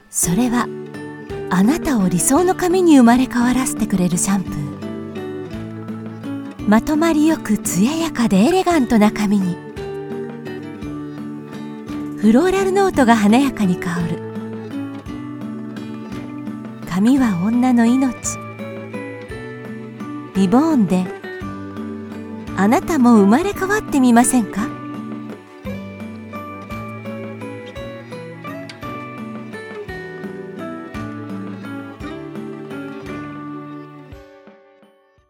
Ideal for corporate narration, IVR, animation, and commercials, she delivers professional voice over services with broadcast-level clarity and speed.
Promos